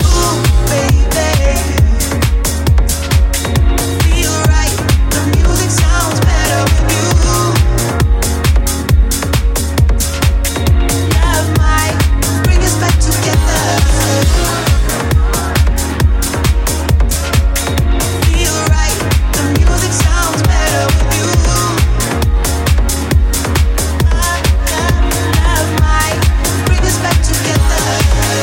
Genere: pop, house, deep, club, edm, remix